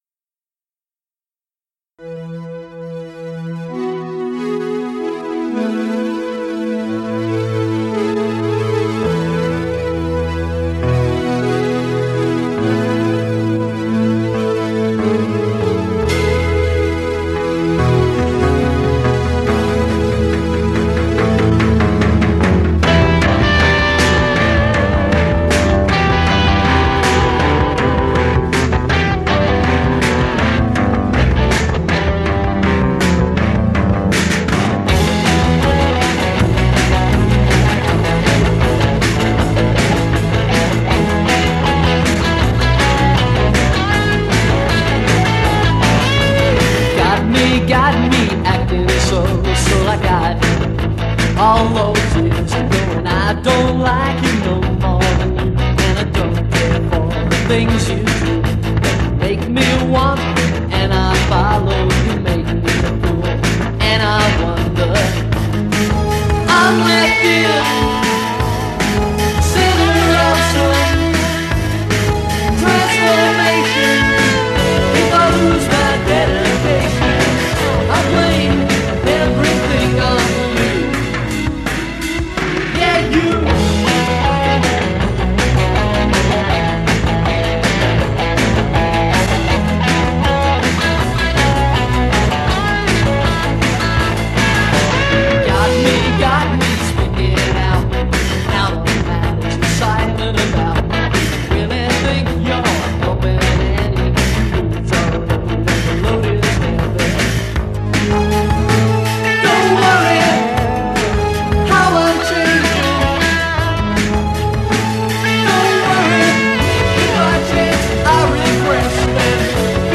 vocals/bass
vocal/drums
guitar
keyboards